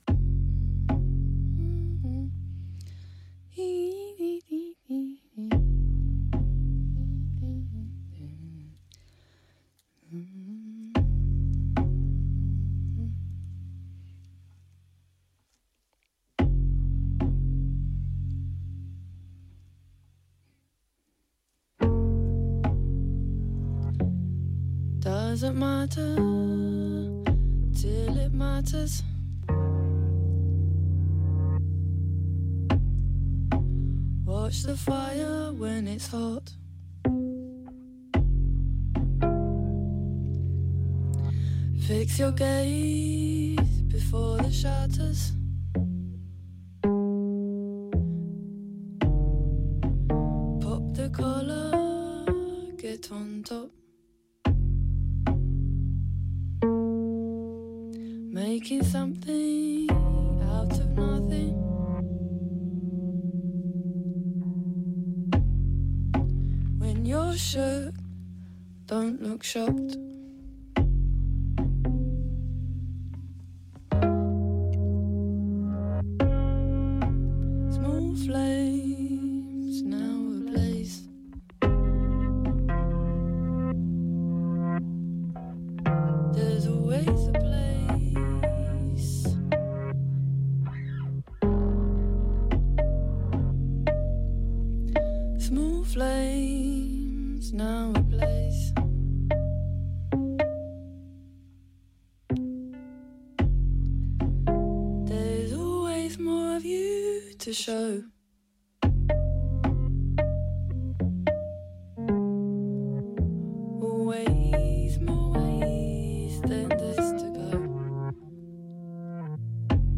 AMBIENT / LEFTFIELD / DRONE JAZZ